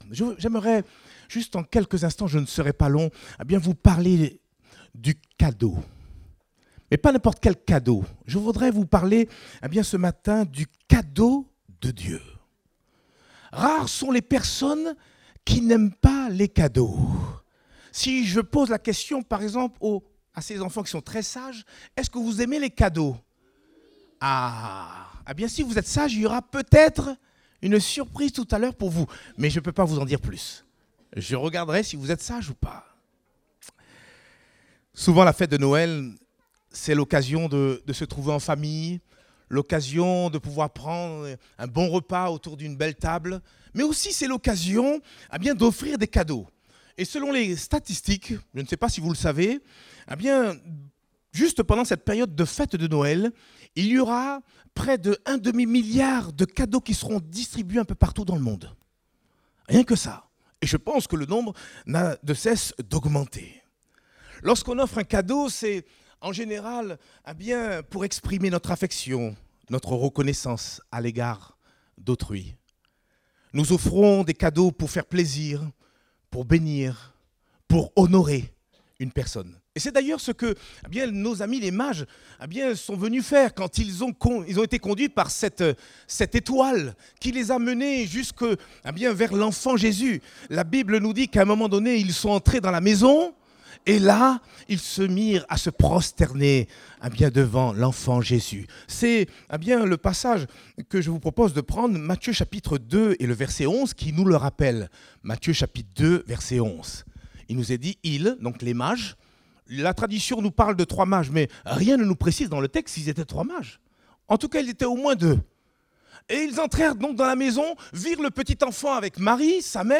Date : 17 décembre 2023 (Culte Dominical)